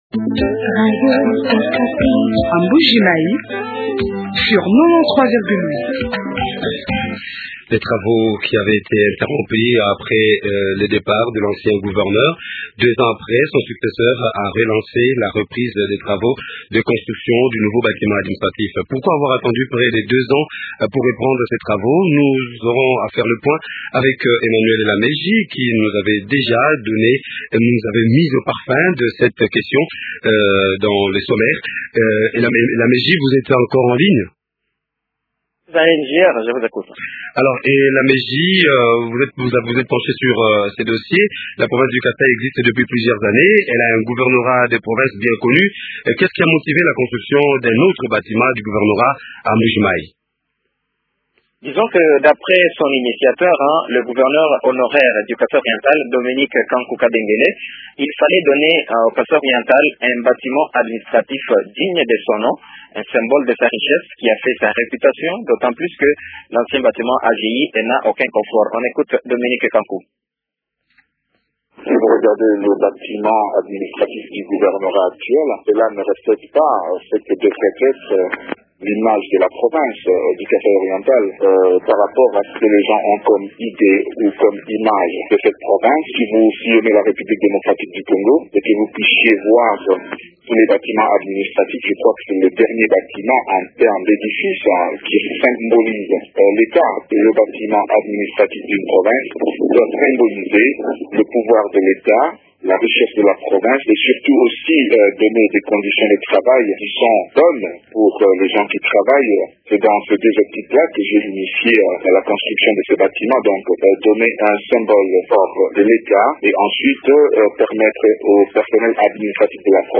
reçoivent Bruno Kazadi, Vice Gouverneur de Province.